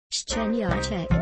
ivona-speech2go-szczeniaczek.mpga